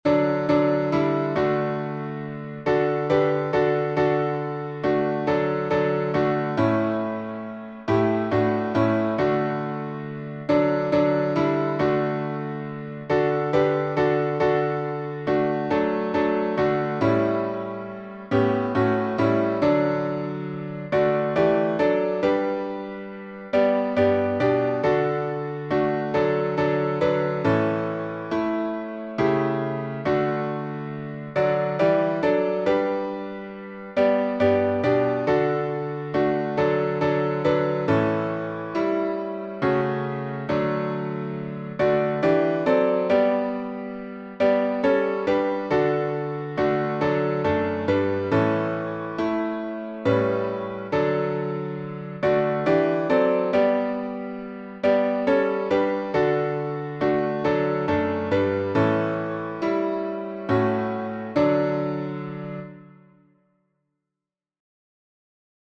The Love of God — alternate chording.
Key signature: D major (2 sharps) Time signature: 3/4